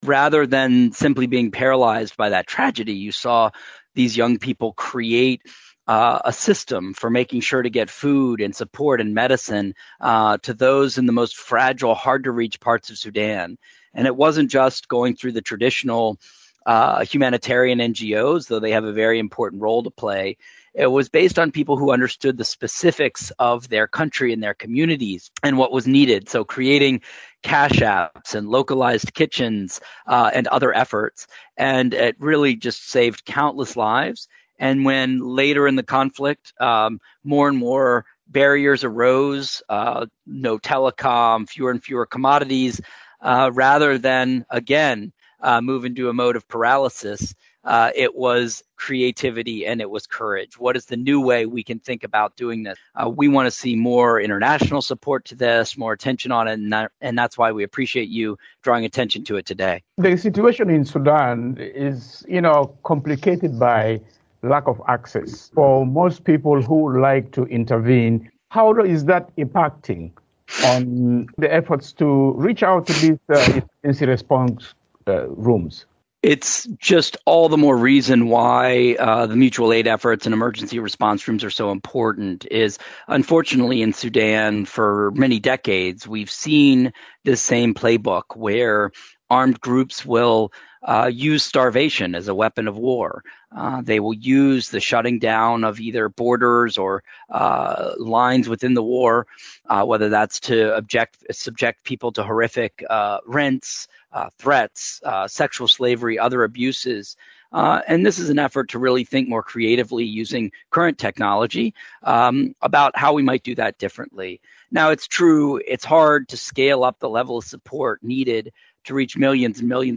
The U.S. Special Envoy for Sudan, Ambassador Tom Perriello, is urging the international community and U.S. allies to support Sudanese humanitarian efforts. He highlights the critical work of Emergency Response Rooms, established by local groups at the onset of the conflict and now struggling due to lack of support. Perriello calls on the rival Sudan Armed Forces and paramilitary Rapid Support Forces to cease hostilities and allow access for local and international aid groups.